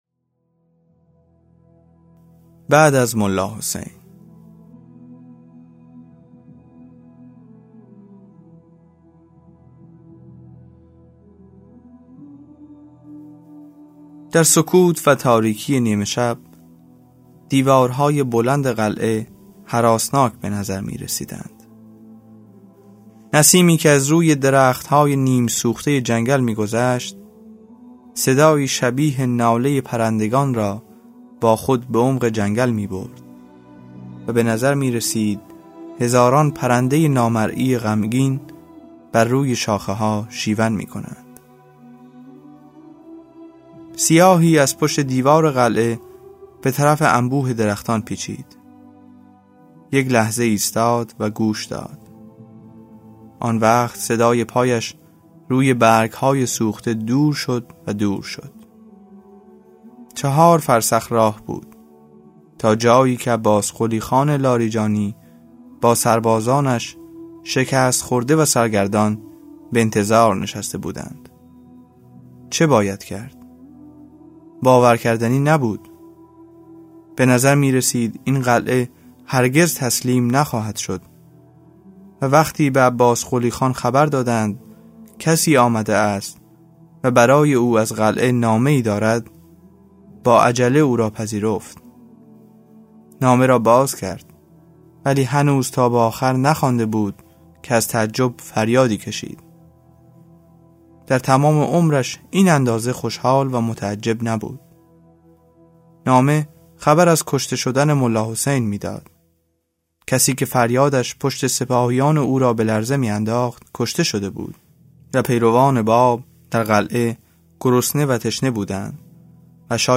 کتاب صوتی سالهای سبز | تعالیم و عقاید آئین بهائی